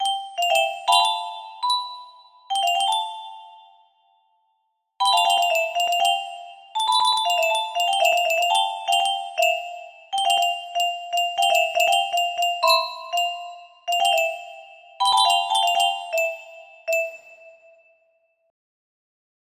Zijderoute test music box melody